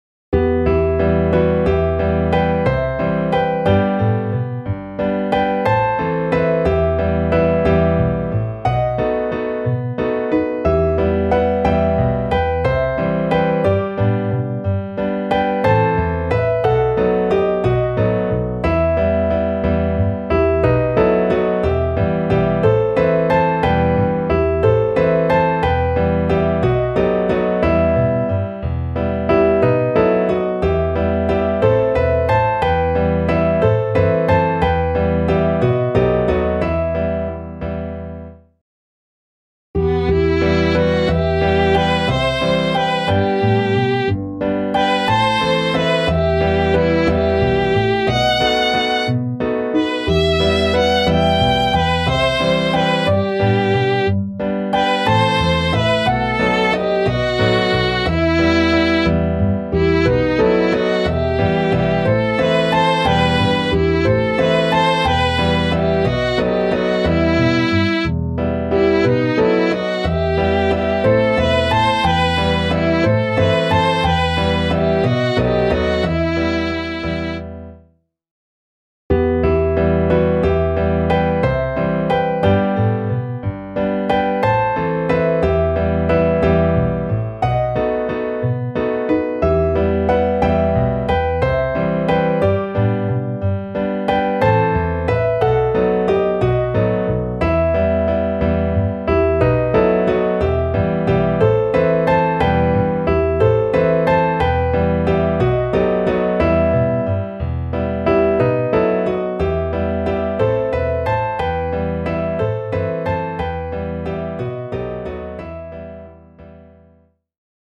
Klaviersatz